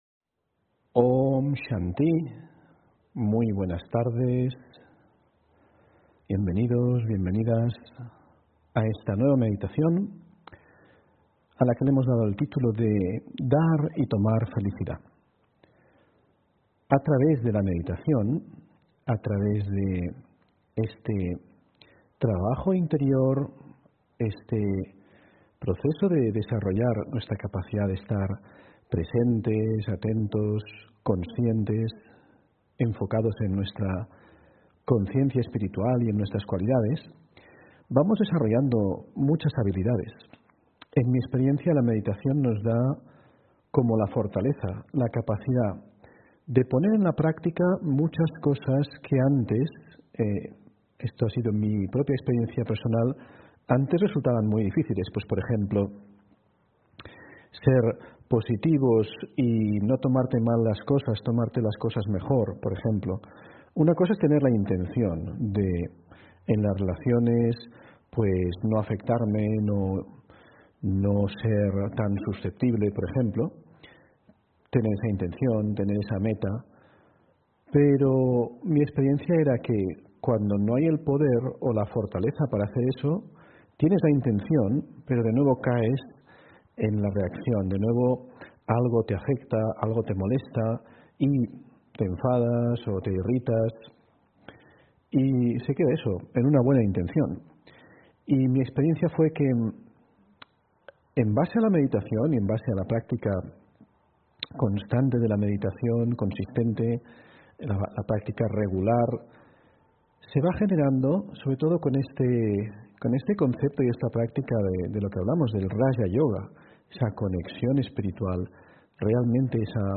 Audio conferencias
Meditación Raja Yoga: Dar y tomar felicidad (24 Septiembre 2020) On-line desde Madrid